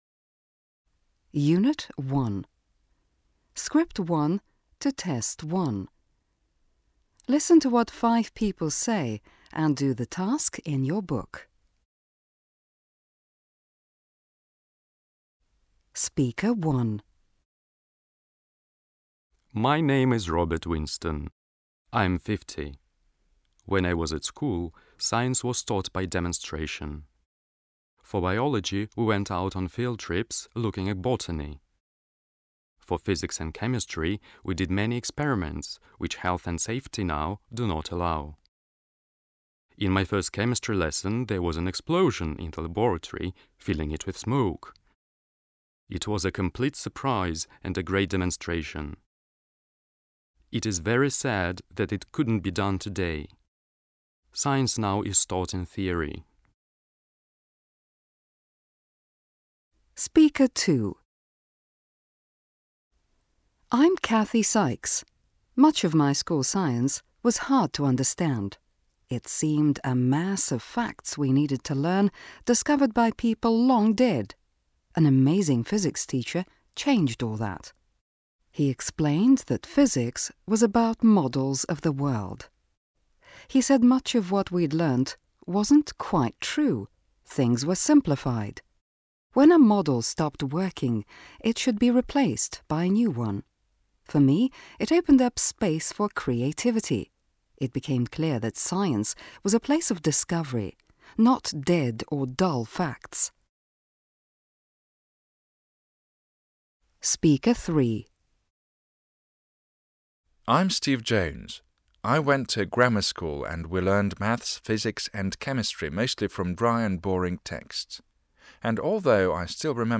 Аудиокурс английского языка Афанасьевой за 8 класс — урок 2